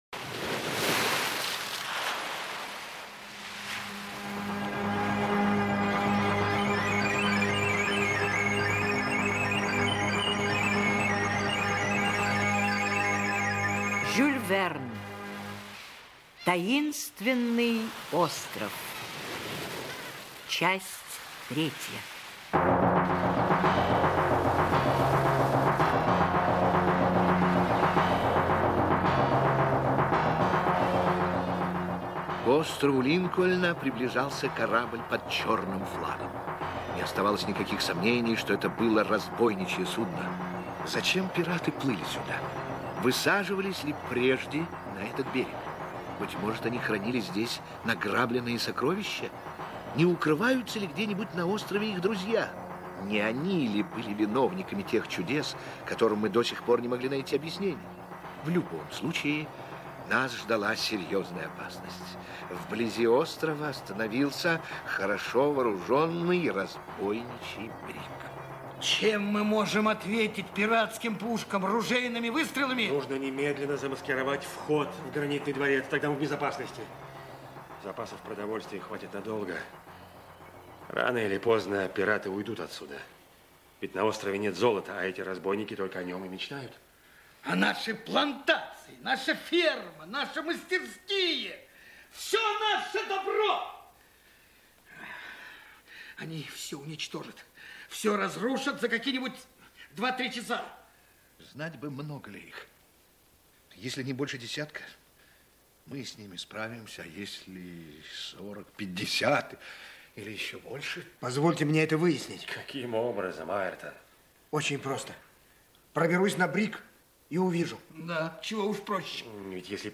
Таинственный остров - аудио роман Верна Ж. Роман Жюля Верна про пятерых смельчаков, улетевших на воздушном шаре из взятого южанами города.